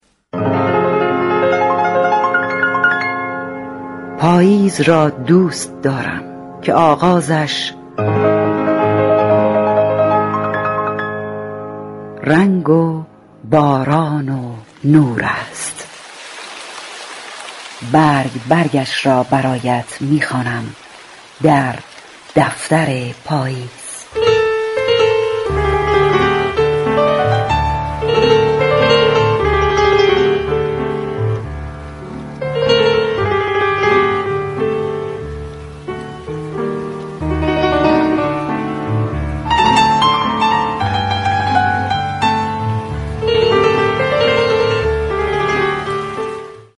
خوانش داستانها، اشعار و متون ادبی